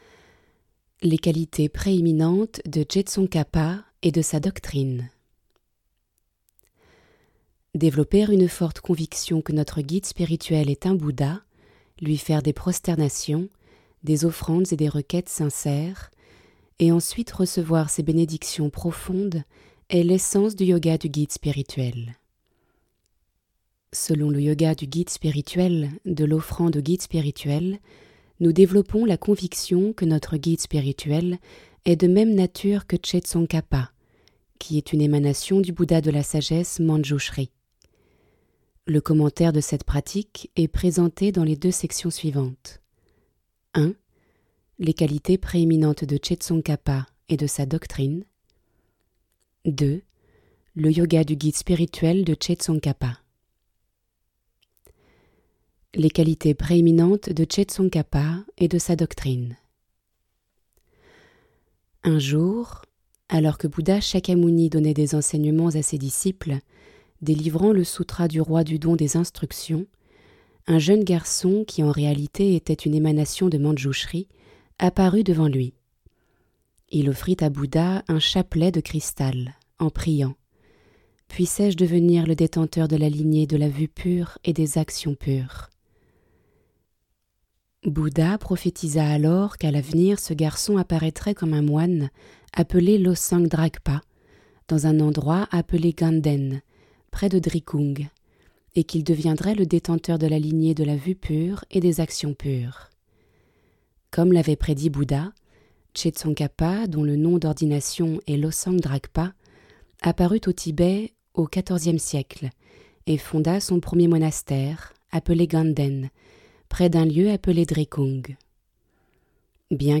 0% Extrait gratuit Disponible en livre audio Grand Trésor de mérite 20 , 00 € Grand Trésor de mérite Guéshé Kelsang Gyatso Éditeur : Editions Tharpa Paru le : 2022-12-07 Ce livre présente des instructions très claires sur la manière de s'en remettre de façon parfaite à un guide spirituel, la fondation de tous les accomplissements spirituels.